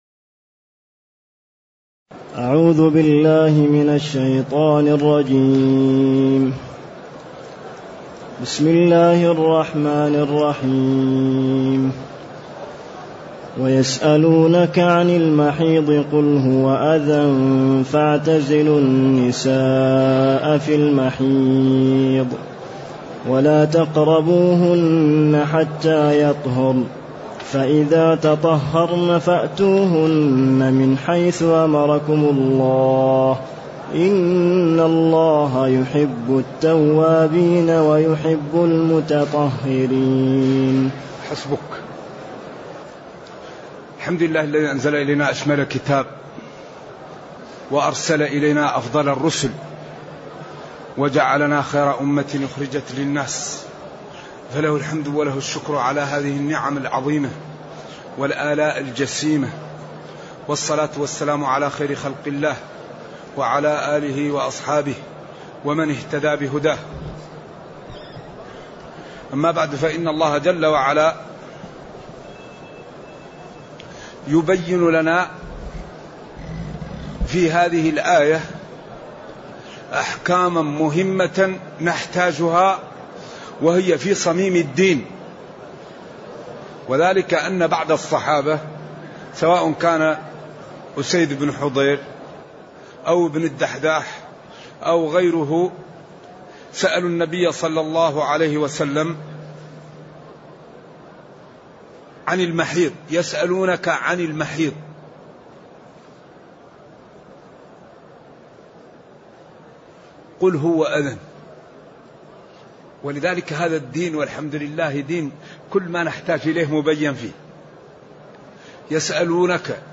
تاريخ النشر ٦ رمضان ١٤٢٨ هـ المكان: المسجد النبوي الشيخ